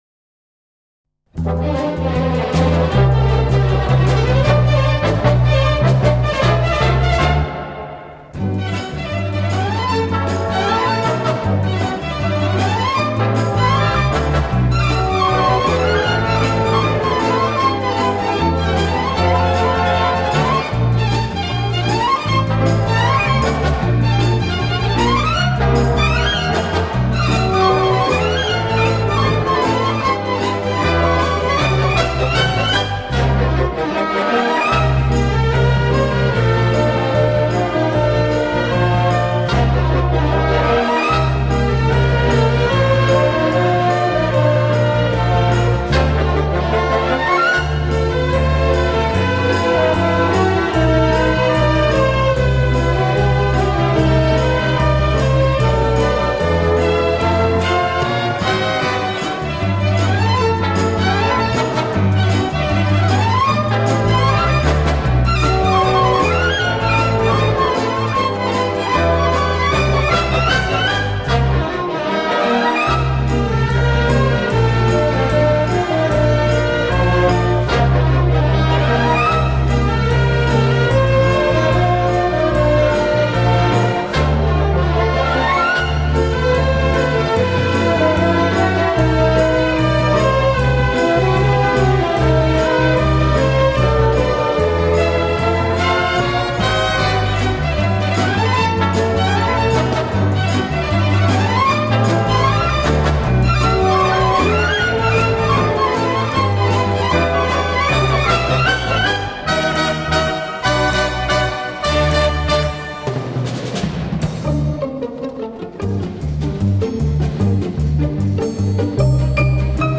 И спасибо за качественный звук.